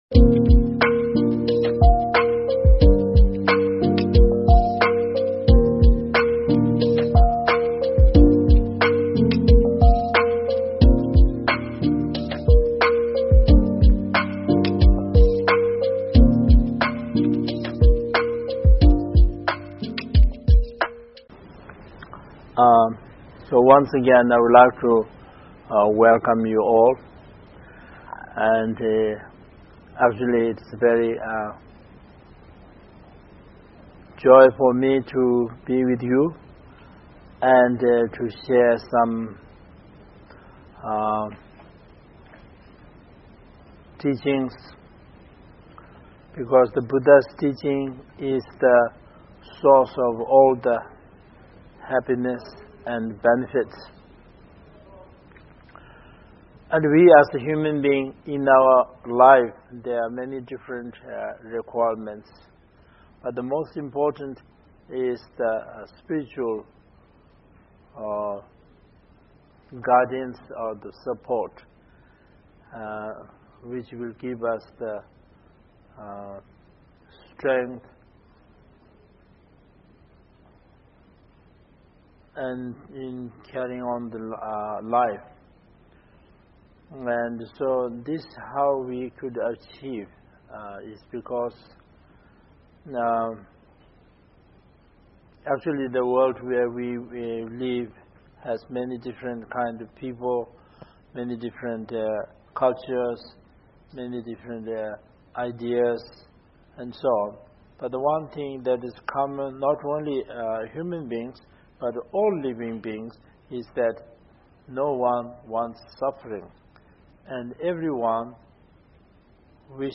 Topic: Mindfulnessand the Buddhist Path: A Public Talk Author: H.H. the Sakya Trichen Venue: New York City, USA Video and Audio Source: Anonymous shared the recordings
5.Mindfulnessand the Buddhist Path: A Public Talk